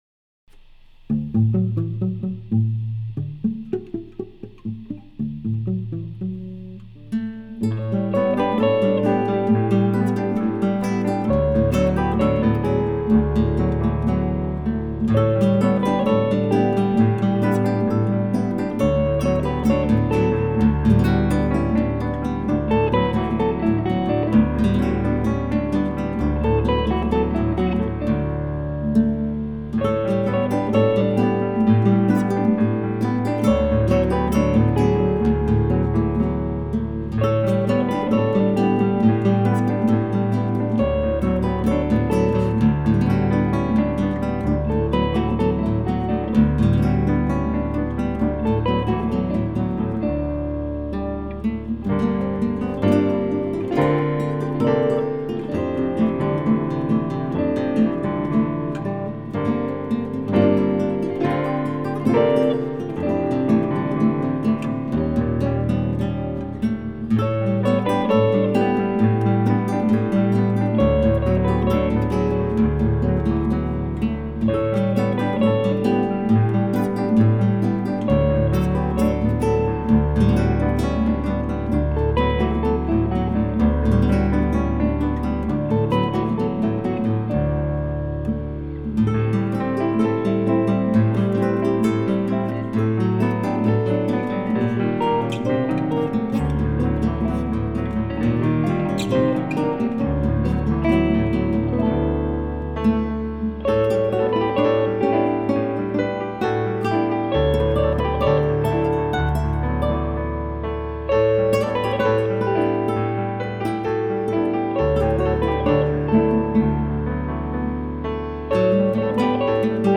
Classical Blues Cabaret